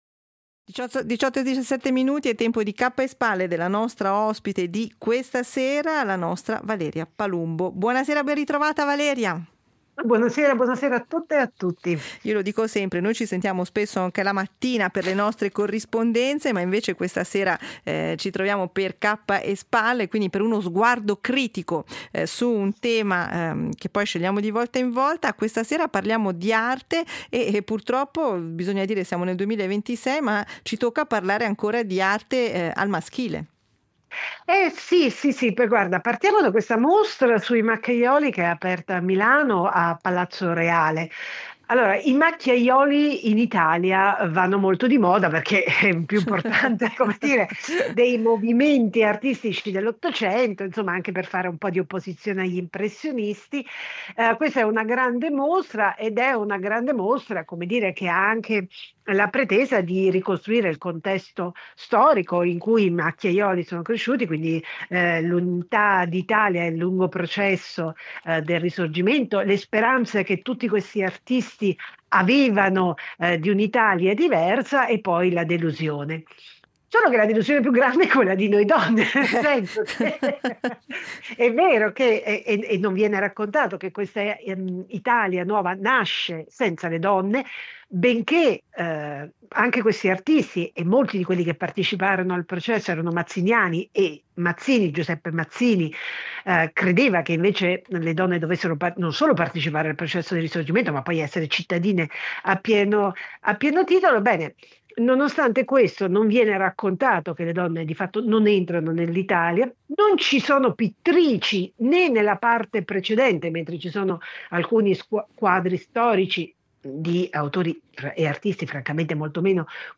Editoriali tra cultura, attualità e sguardi sul costume